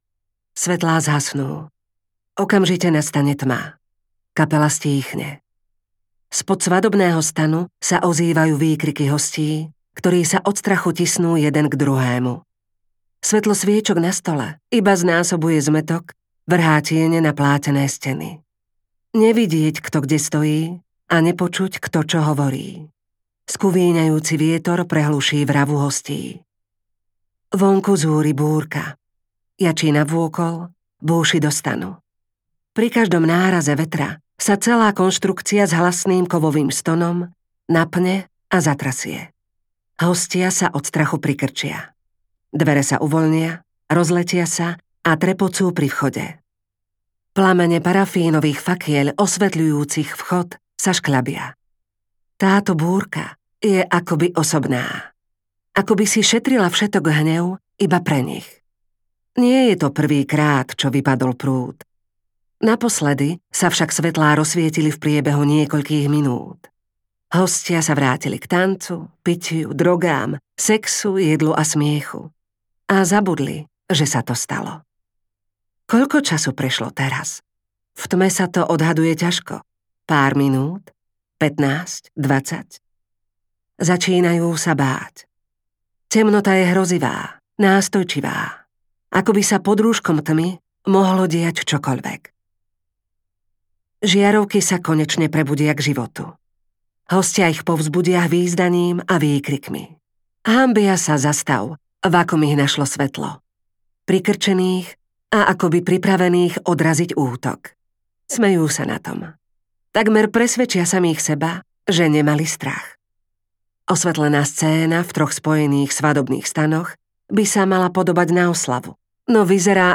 Zoznam hostí audiokniha
Ukázka z knihy